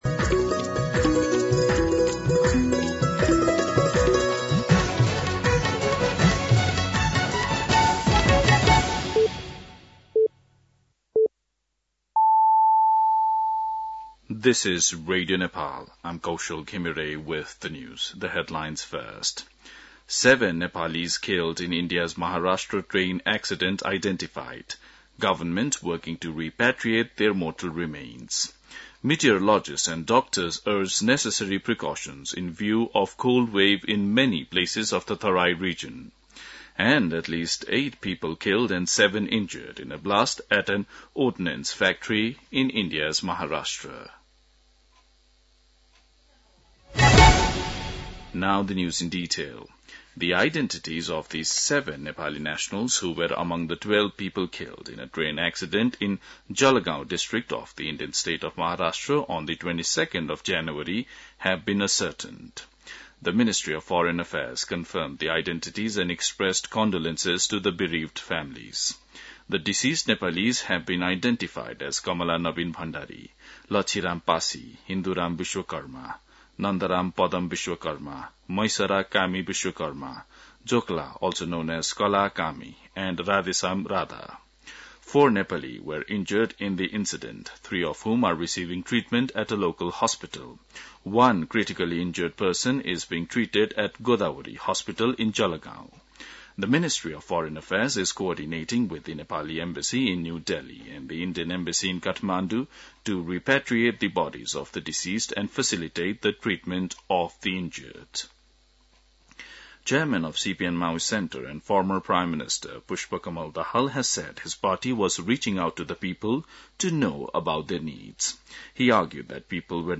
दिउँसो २ बजेको अङ्ग्रेजी समाचार : १३ माघ , २०८१
2pm-English-News-10-12.mp3